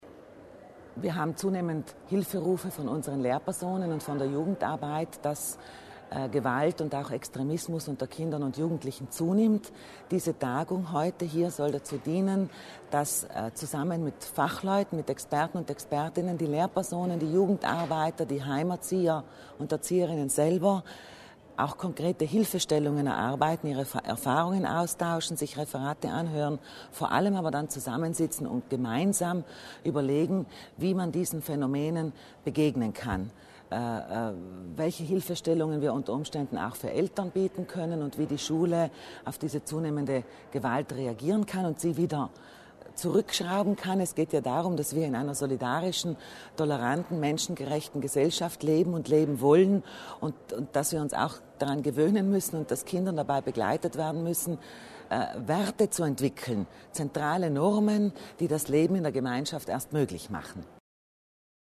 Den Rahmen bildete eine von Deutschem Schulamt und Pädagogischem Institut veranstaltete Tagung. Die Politik habe die Aufgabe, der Verbreitung rechtsradikalen Gedankenguts vorzubeugen, sagte LRin Sabina Kasslatter Mur bei der Eröffnung.